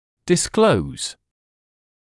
[dɪs’kləuz][дис’клоуз]выявлять, обнаруживать; раскрывать